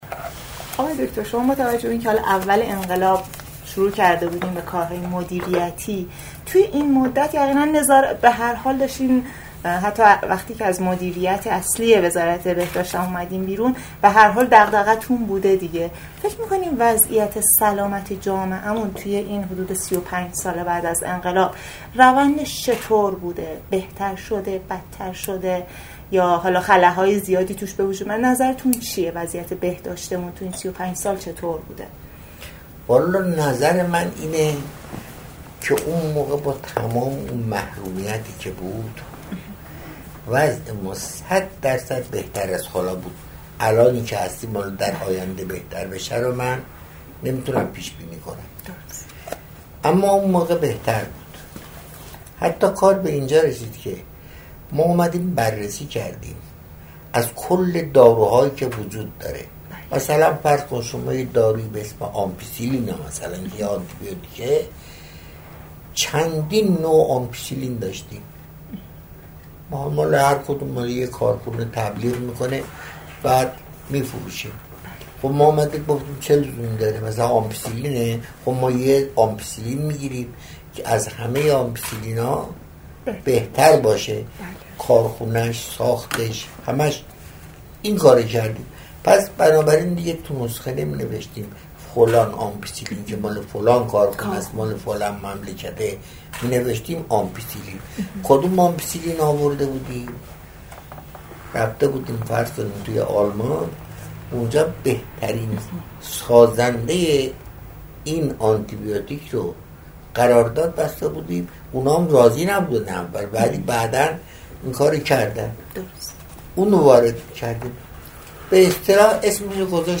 مصاحبه با وزیر بهداشت سه دولت اول بعد از انقلاب؛